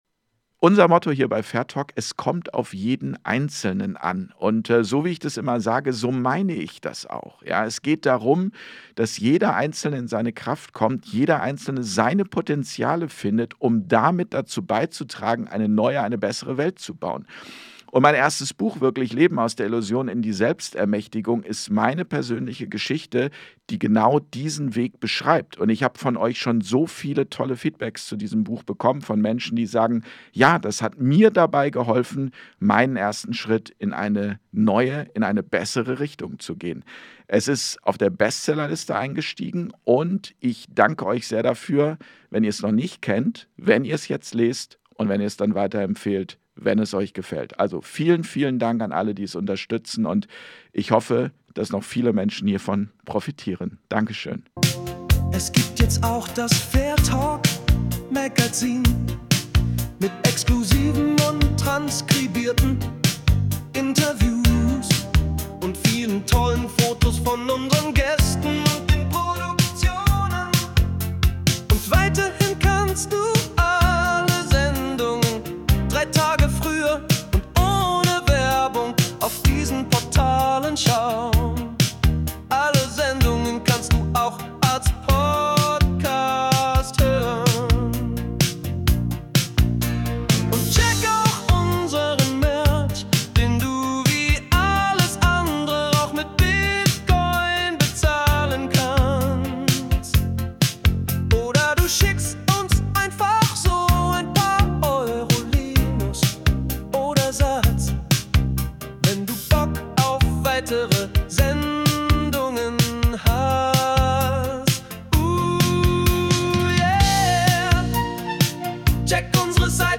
Das Interview-Format